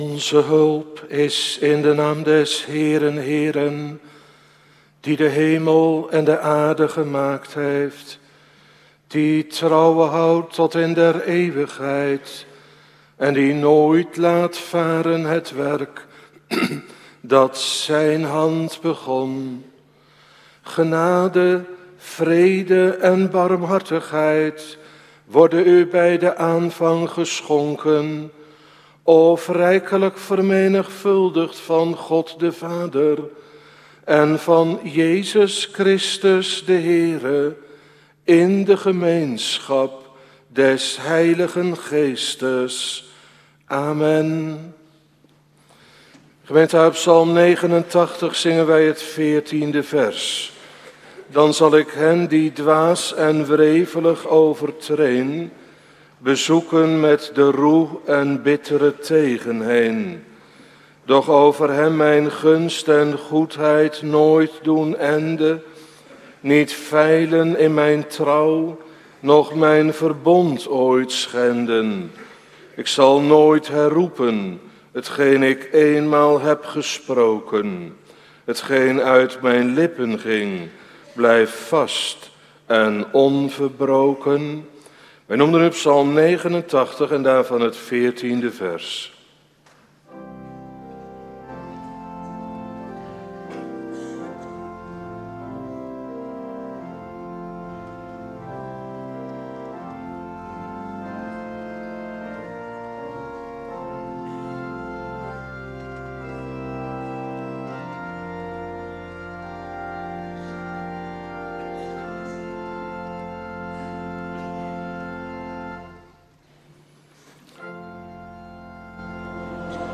Preken terugluisteren